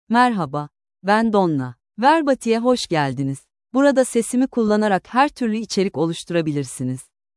DonnaFemale Turkish AI voice
Voice sample
Female
Donna delivers clear pronunciation with authentic Turkey Turkish intonation, making your content sound professionally produced.